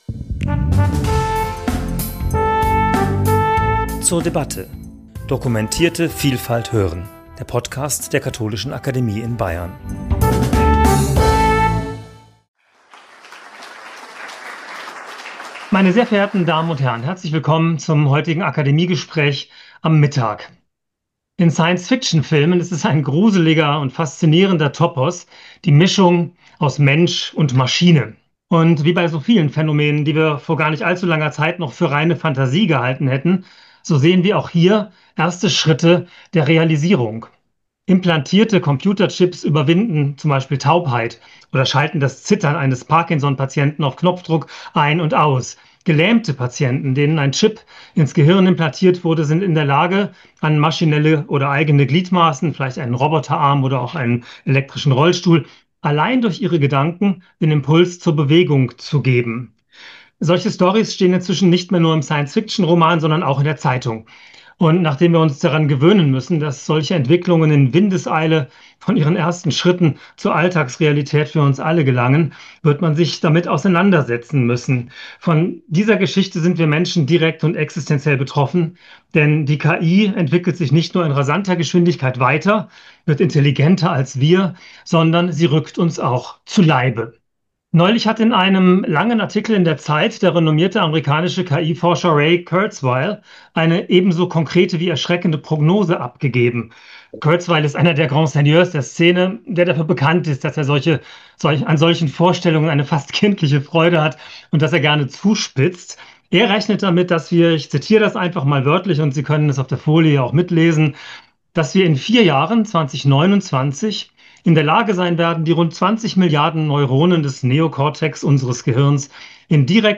Gespräch zum Thema 'KI-Chip im Hirn - Transhumanistische Träume für unser Beziehungsorgan' ~ zur debatte Podcast